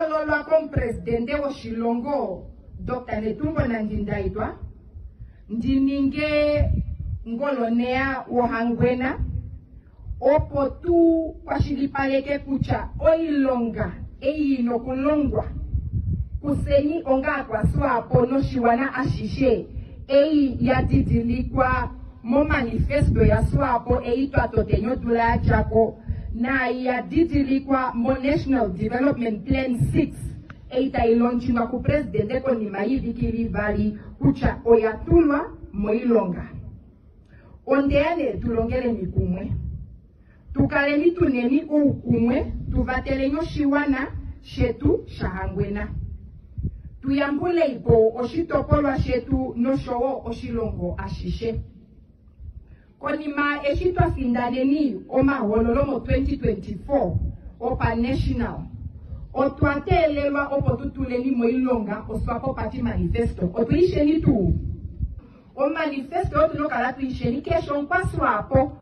Ngoloneya omupe okwa popi mehuliloshiwike momutumba goSWAPO mOngenga.